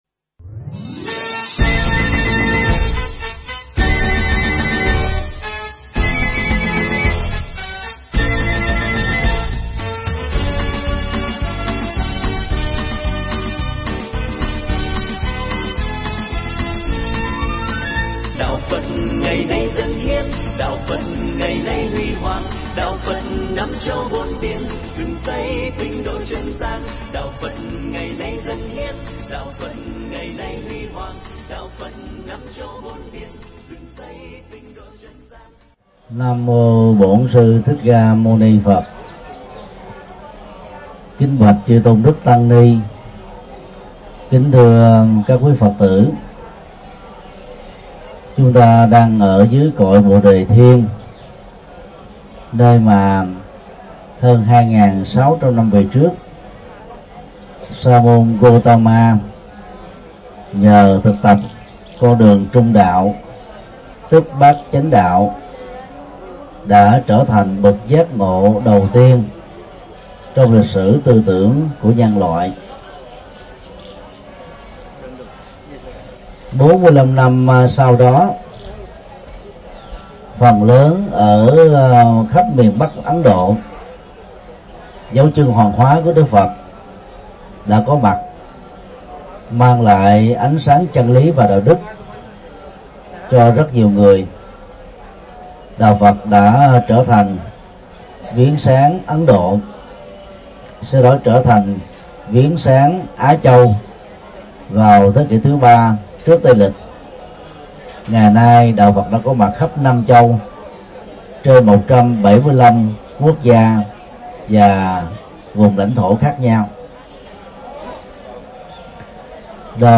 Mp3 Pháp Thoại Những ngộ nhận nên tránh đối với đạo Phật
Giảng tại Bồ Đề Đạo Tràng – Ấn Độ, ngày 15 tháng 3 năm 2015